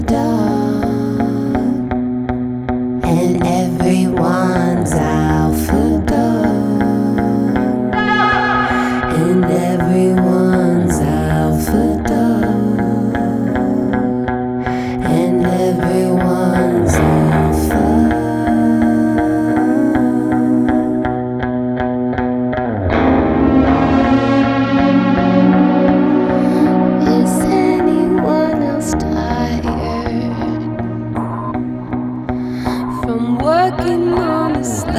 Alternative Electronic
Жанр: Альтернатива / Электроника